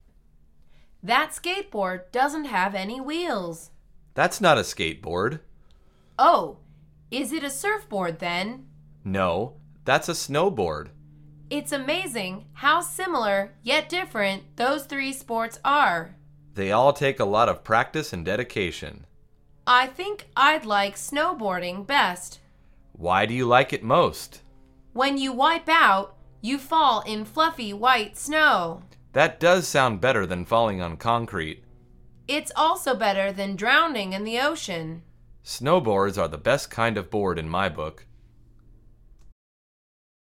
در واقع، این مکالمه مربوط به درس شماره دوازدهم از فصل ورزش از این مجموعه می باشد.